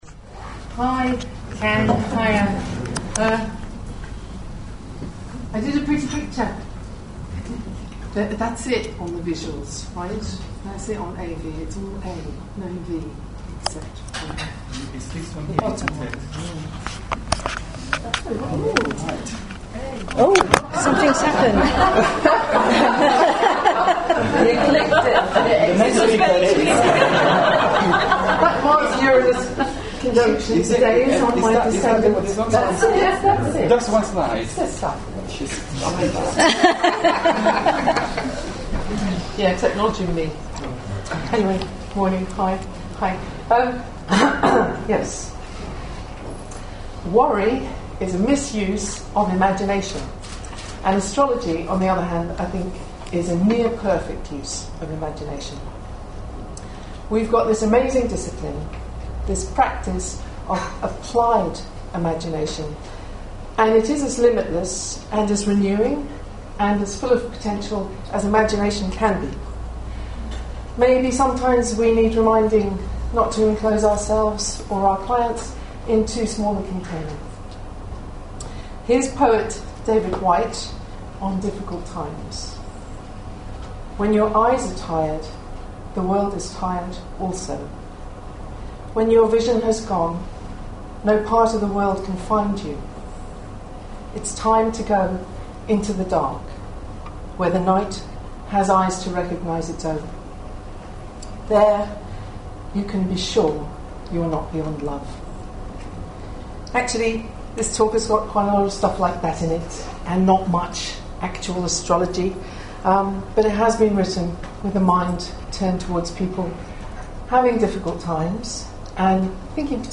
Faculty Open Day 2013 – Faculty of Astrological Studies